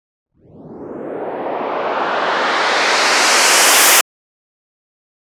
BWB UPGRADE3 FX RISE (4).wav